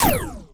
sci-fi_weapon_laser_small_02.wav